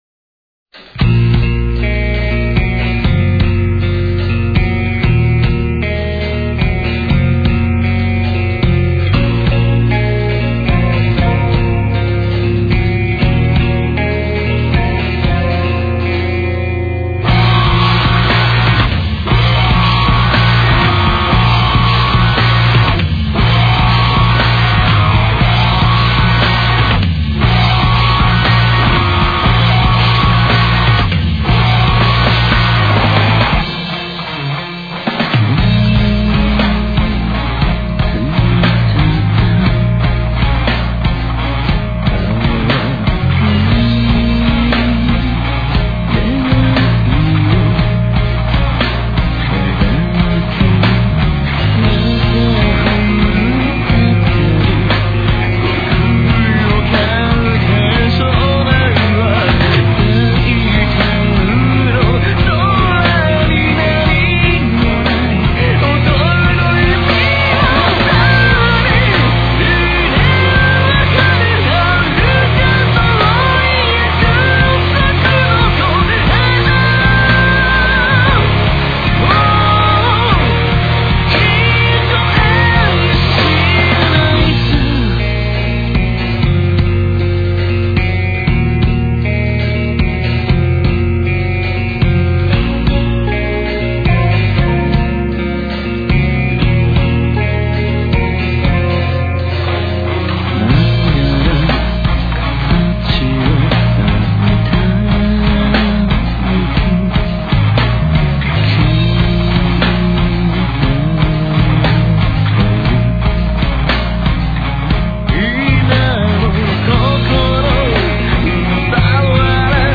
2 songs in bad quality.